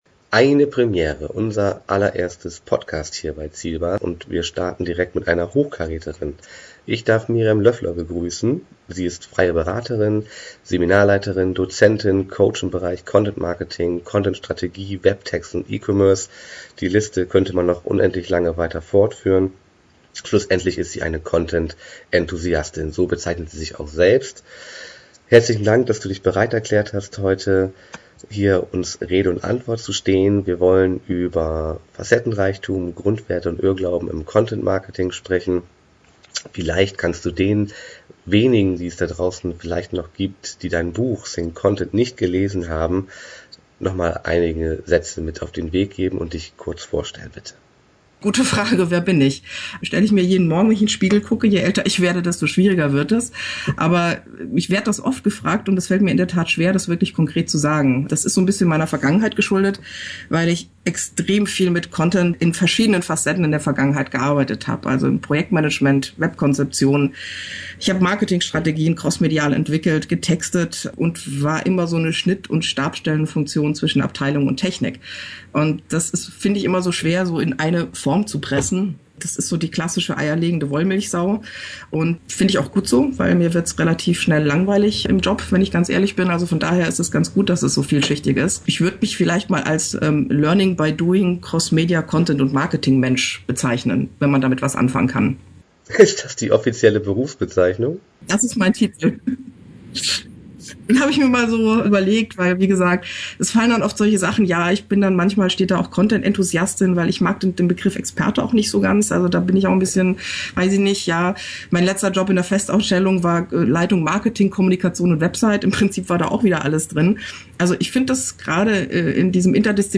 content-marketing-interview.mp3